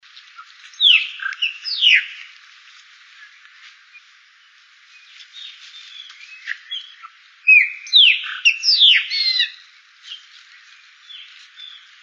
Graúna (Gnorimopsar chopi)
Nome em Inglês: Chopi Blackbird
Fase da vida: Adulto
Localidade ou área protegida: Parque Nacional Iguazú
Condição: Selvagem
Certeza: Fotografado, Gravado Vocal